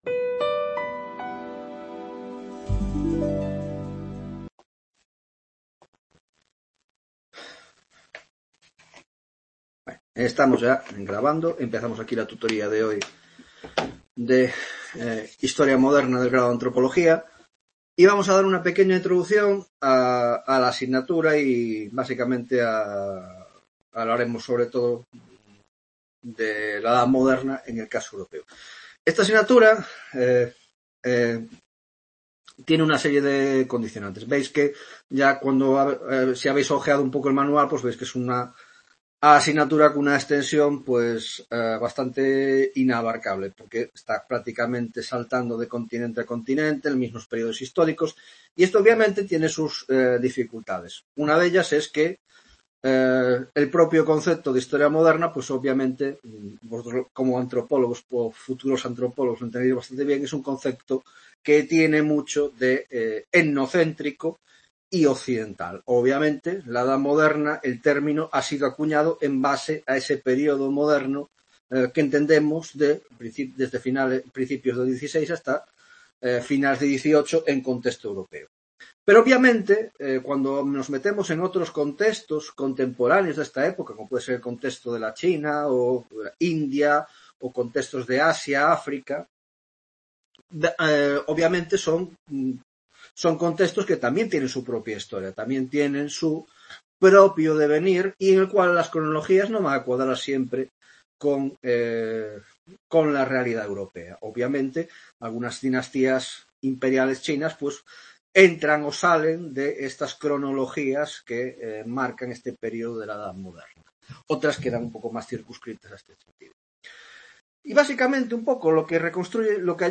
1ª Tutoria Historia Moderna (Grado de Antropologia Social y Cultural): 1) Introducción general a la asignatura; 2) Contexto de la Edad Moderna Europea (1ª parte): Aspectos culturales, Demografia, Organización Social y Economia